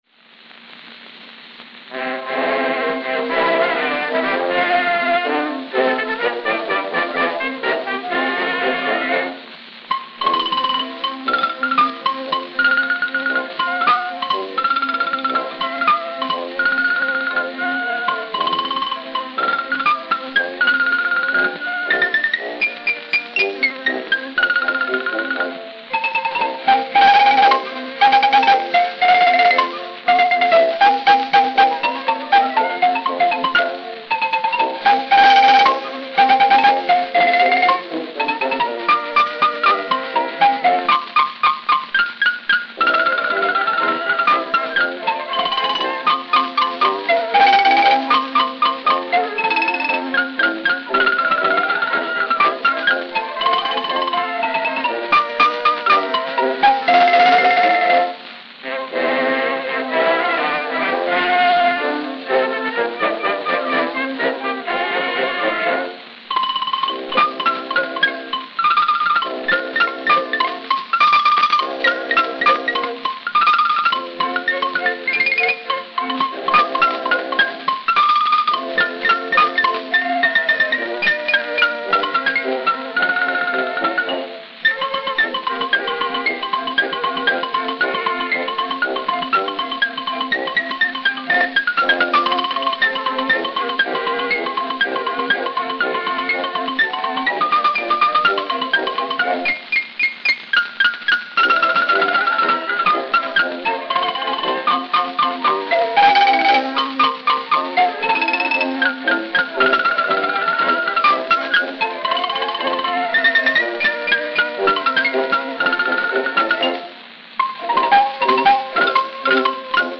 Xylophone